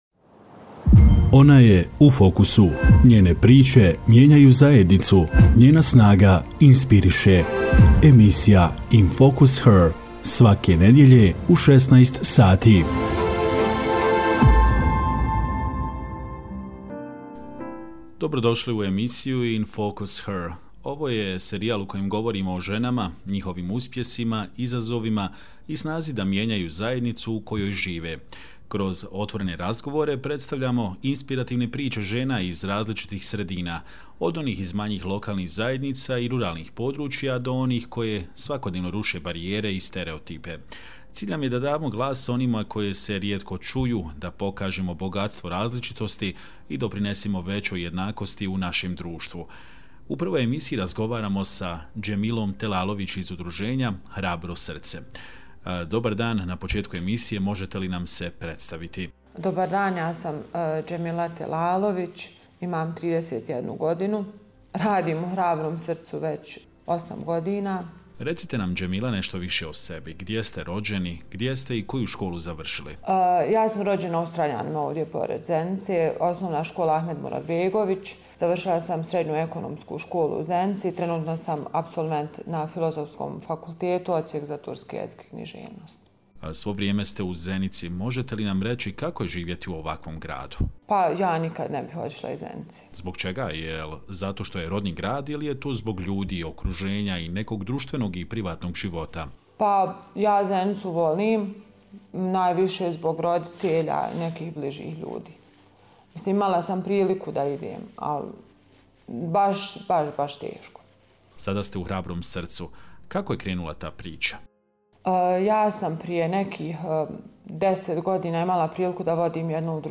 Kroz razgovor je otvorila svoje životne i profesionalne priče, govoreći o motivima, izazovima i iskustvima koje je oblikovala borba za dostojanstveniji život ljudi u potrebi.